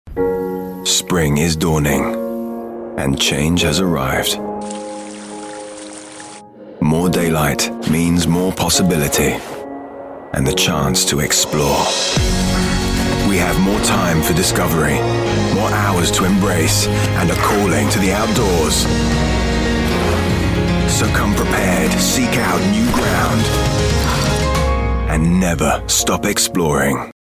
STYLE: Filmic
London/Neutral, Contemporary/Textured/Cool